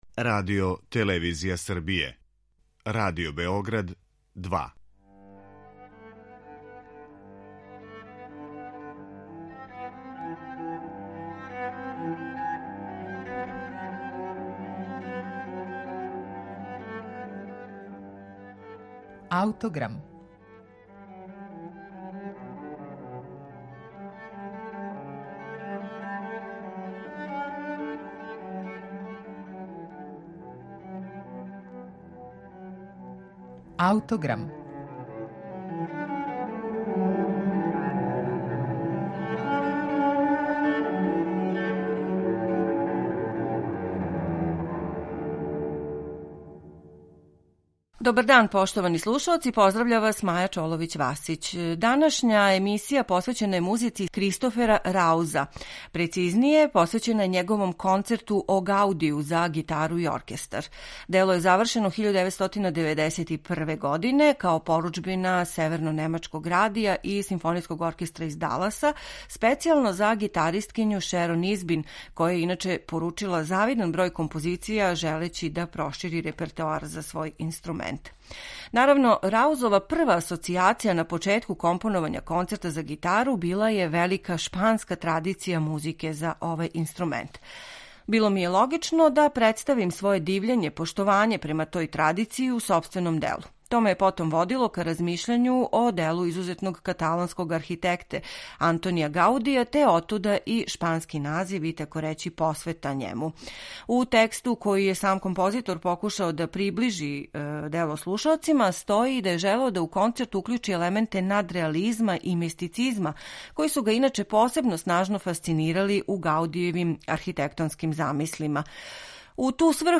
Концерт за гитару и оркестар